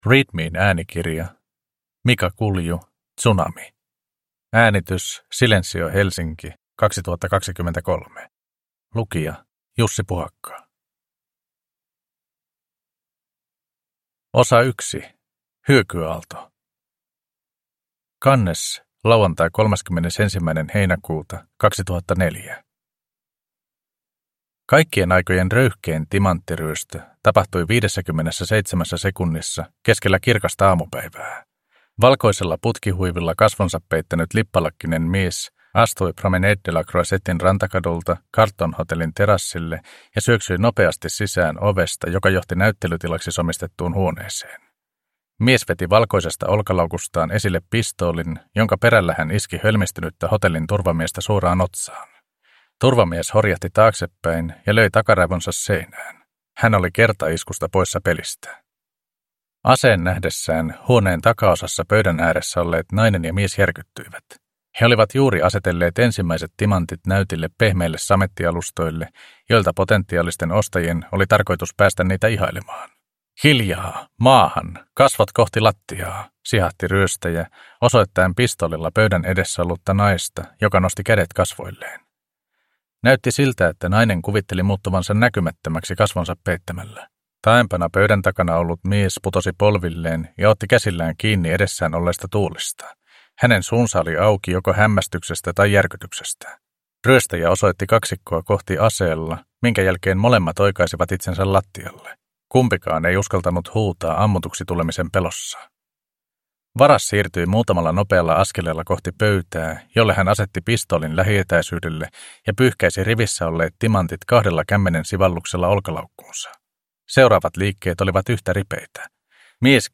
Tsunami (ljudbok) av Mika Kulju